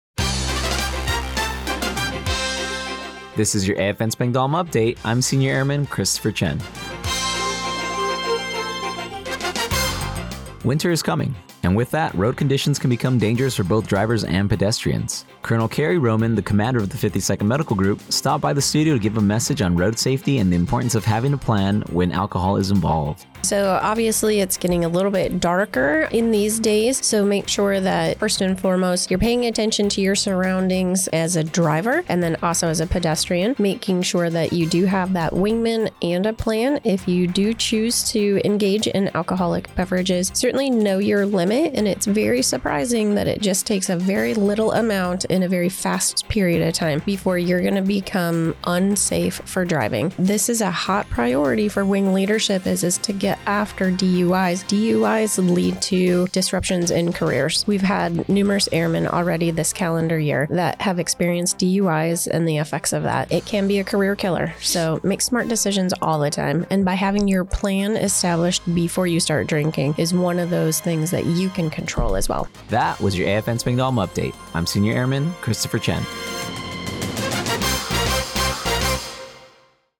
The following was the radio news report for AFN Spangdahlem for Oct. 28, 2024.